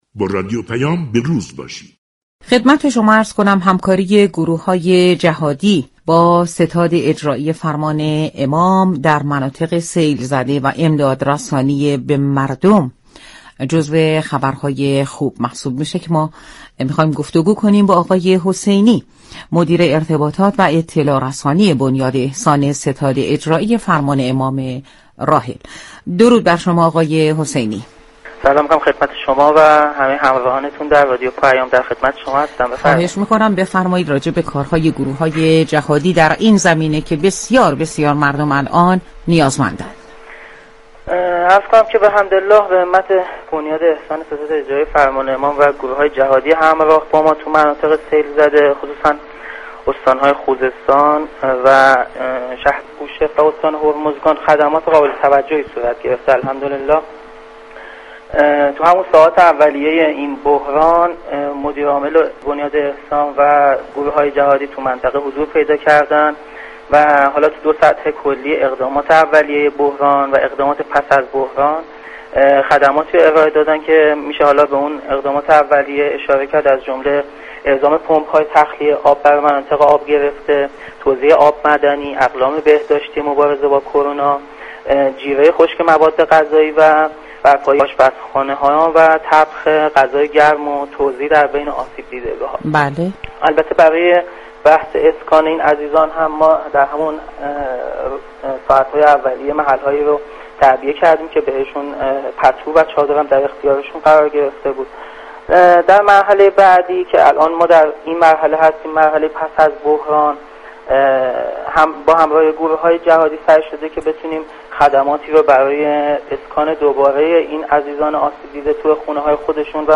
در گفتگو با رادیو پیام ، جزئیاتی از كمك رسانی‌های بنیاد احسان به مناطق آسیب دیده از سیل در جنوب كشور را بیان كرد .